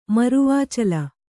♪ maruvācala